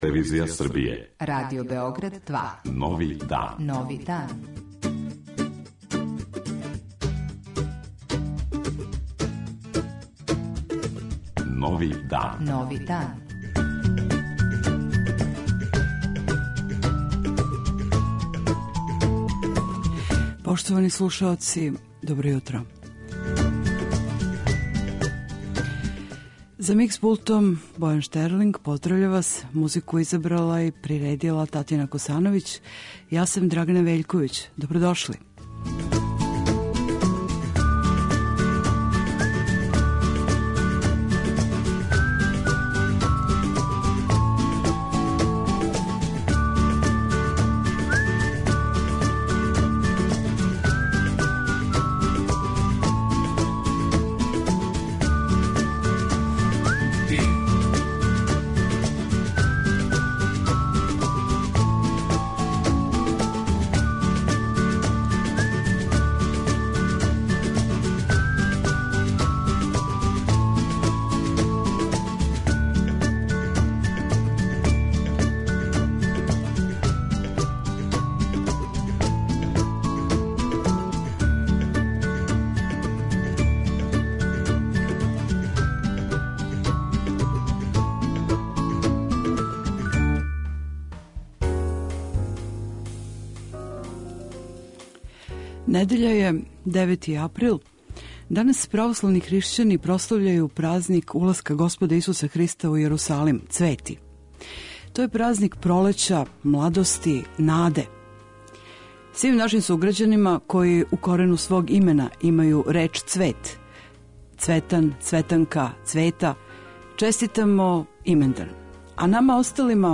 Како је прослављена Врбица у Врању и шта се дешава у култури овог града и у Пчињском крају, а како се прослављају Цвети и шта се догађа у култури Зајечара и Тимочког краја? Чућемо и извештај из Шапца...